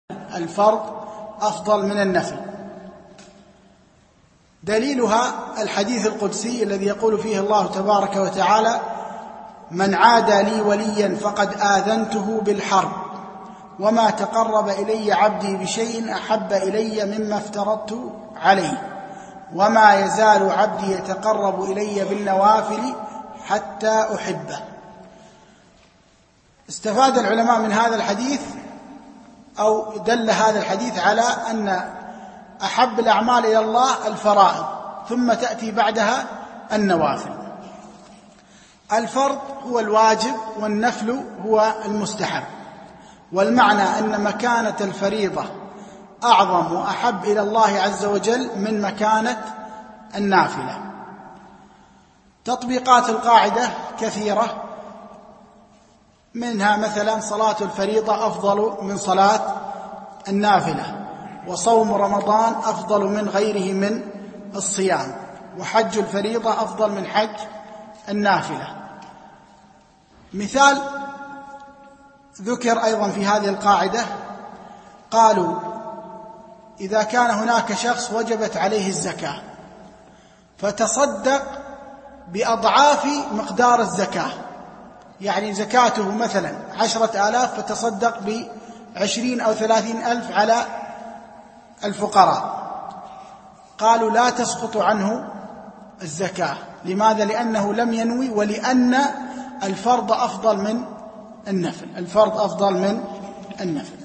MP3 Mono 22kHz 32Kbps (VBR)